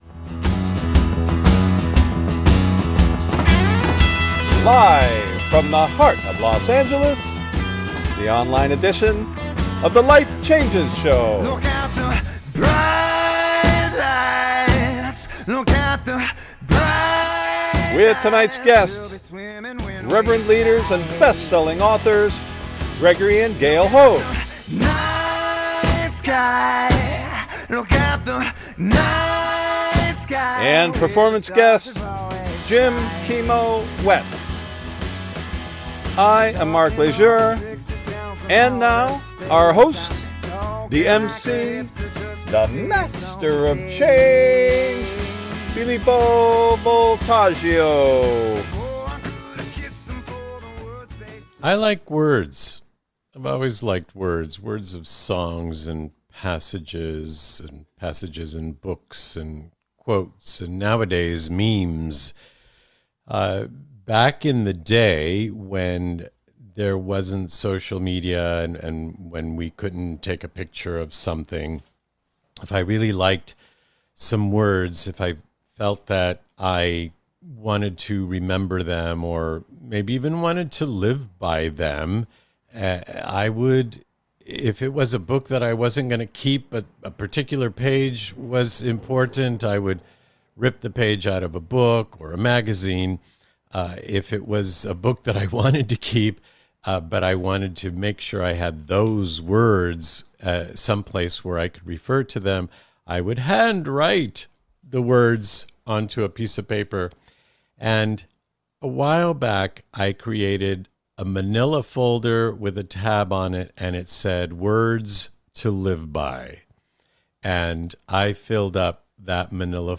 Interview Guests
and Performance Guest, Grammy Winner, World’s Foremost “Ki ho’alu,” (Hawaiian “Slack Key”), Guitar Artist, Jim “Kimo” West